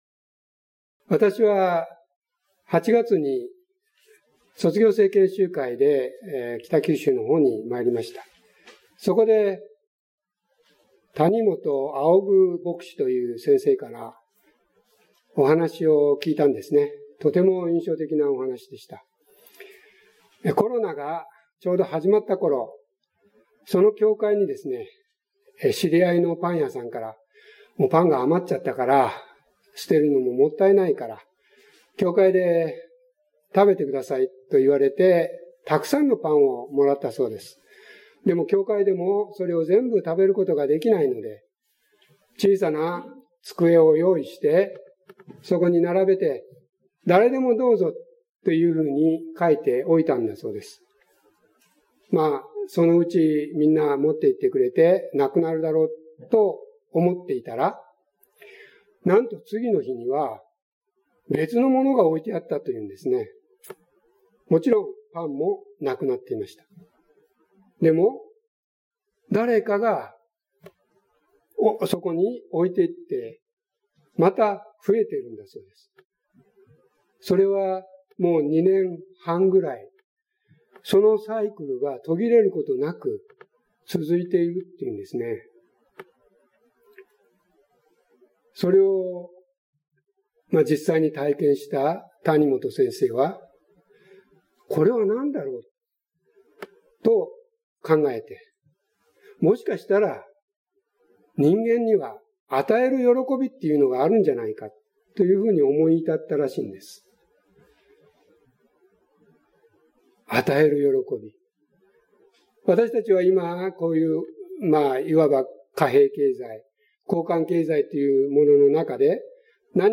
9月25日礼拝説教「与える喜び」